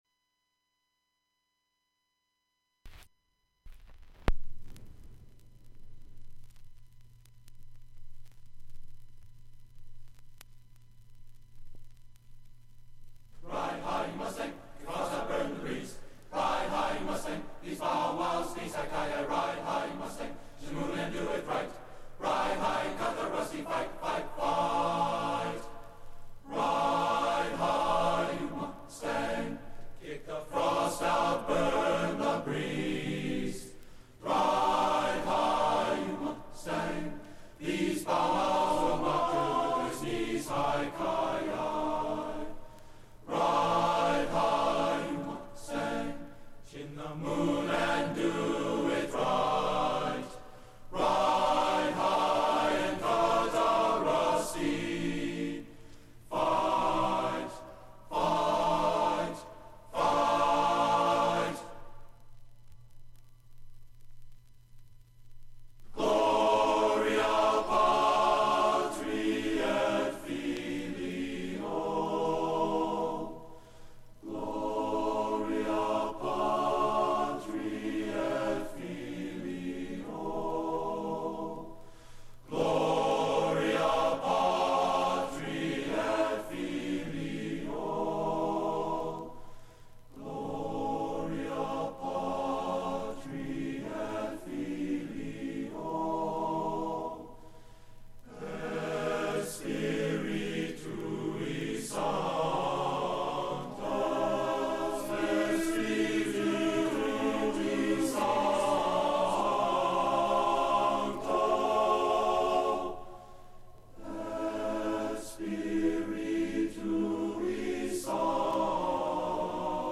Includes a recording of the concert, and the album art from front, back, and inside covers.